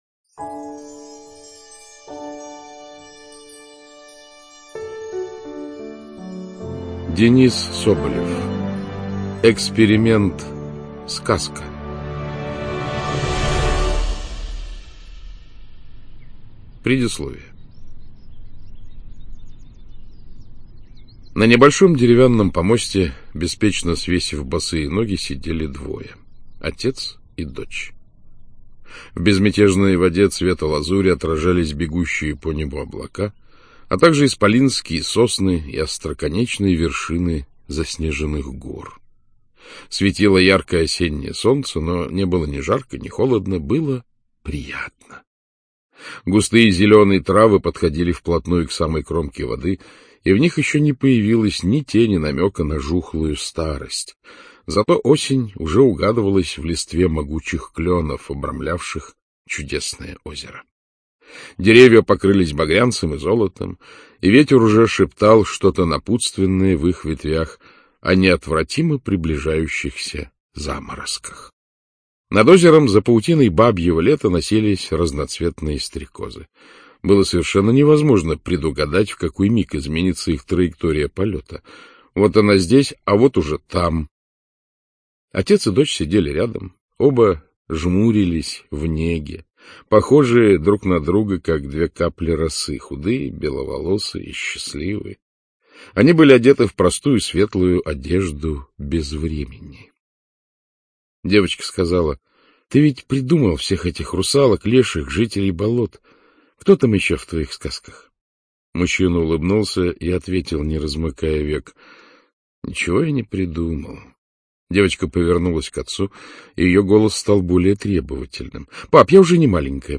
ЧитаетКлюквин А.